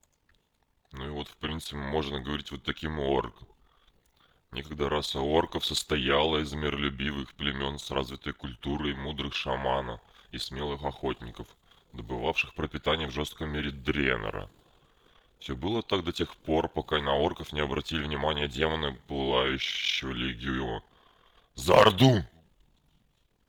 Варианты изменения голоса вы можете видеть на скриншоте.
Записал я звук в WAV для быстроты стандартными средствами Windows.
Orc Male
orcmale.wav